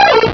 sovereignx/sound/direct_sound_samples/cries/farfetchd.aif at 0ec777dd24c09a1bb428e20ce632c56468a49a83
farfetchd.aif